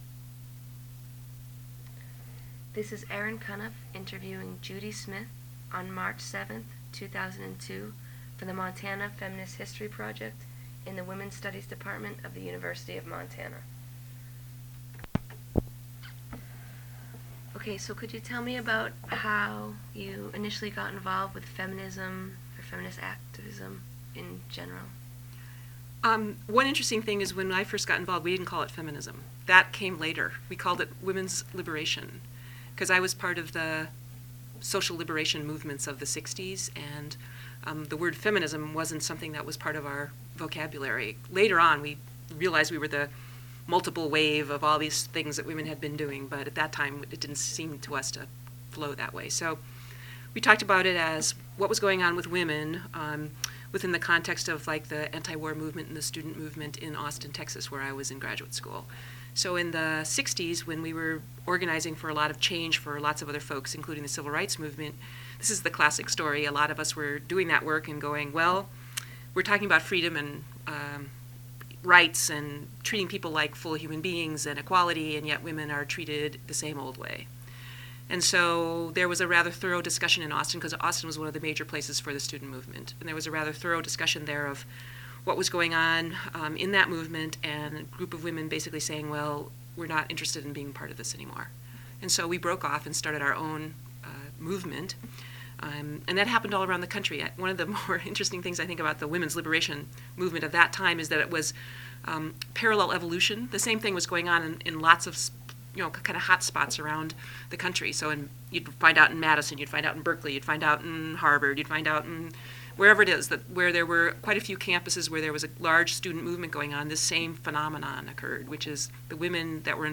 Oral History
1 sound cassette (00:60:00 min.): analog